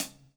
DUBHAT-14.wav